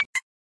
fx " 哔哔声
描述：一声哔哔声
标签： 数字 窦波 高频率